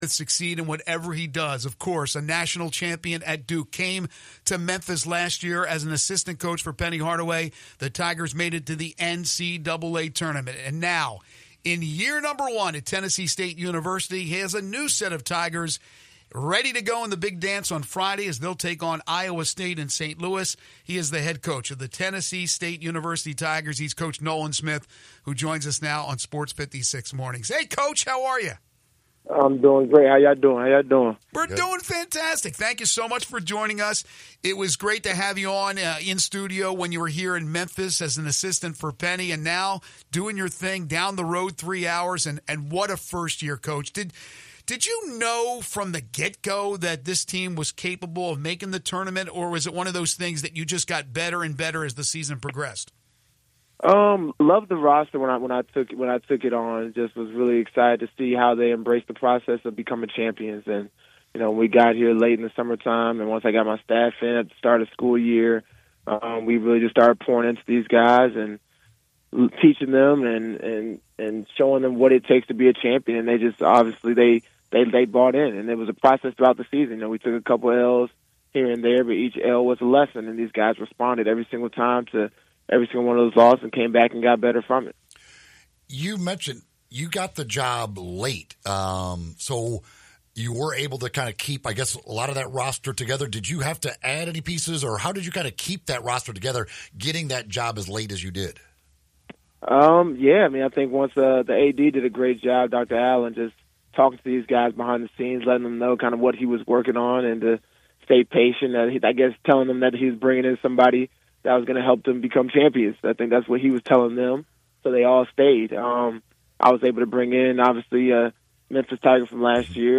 Tennessee St Men's Basketball Head Coach Nolan Smith joins Sports 56 Mornings, discussing his team's success this season, the NCAA Tournament, and more